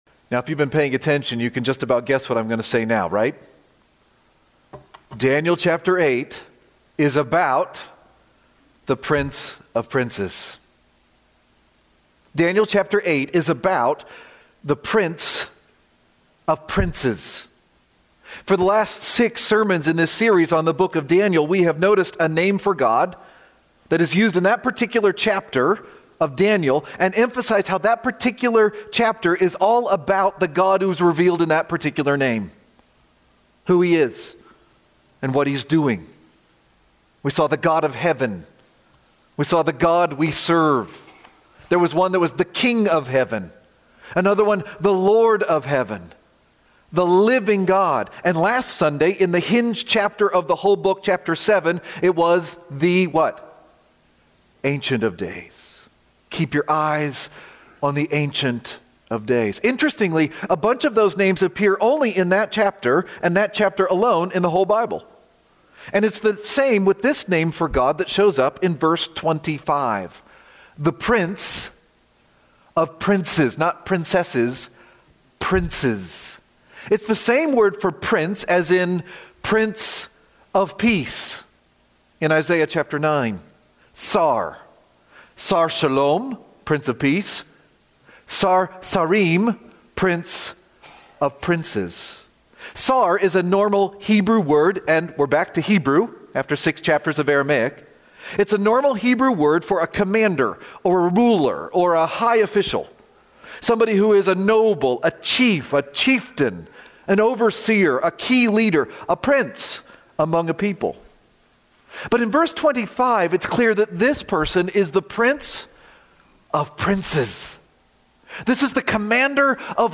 The Prince of Princes :: March 16, 2025 - Lanse Free Church :: Lanse, PA